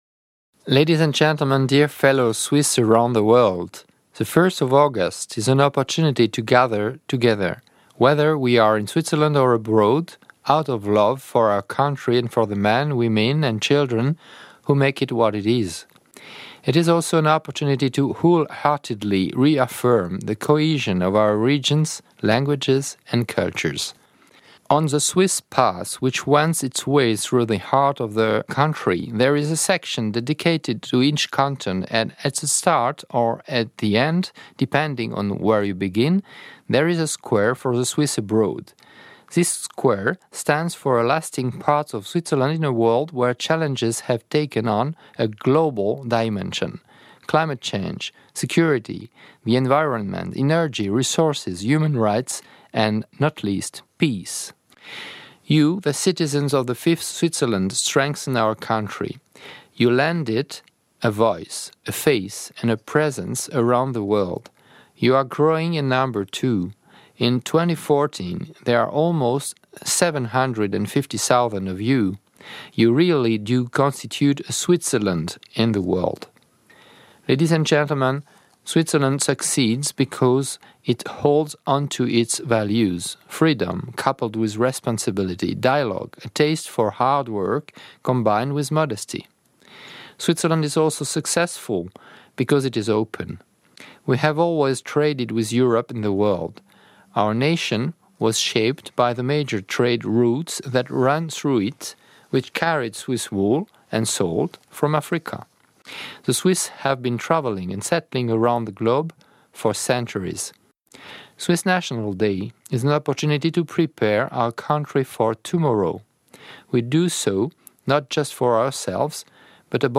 President Didier Burkhalter's speech to the Swiss abroad on the Swiss national day.